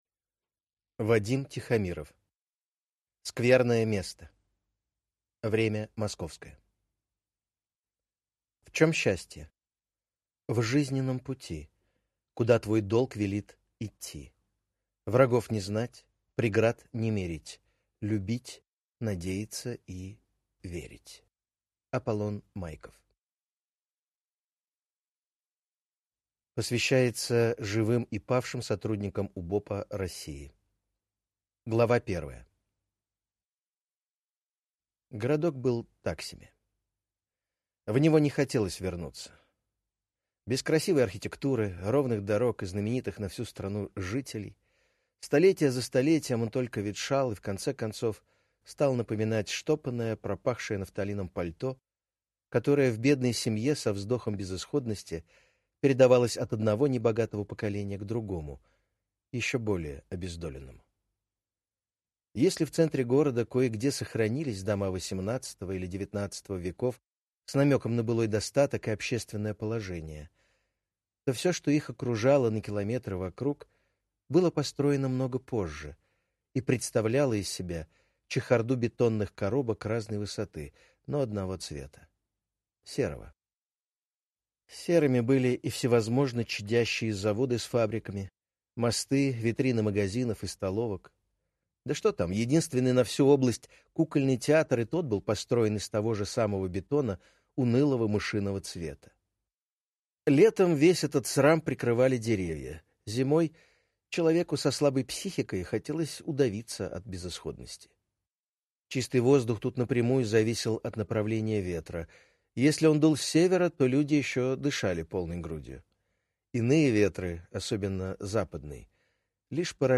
Аудиокнига Скверное место. Время московское | Библиотека аудиокниг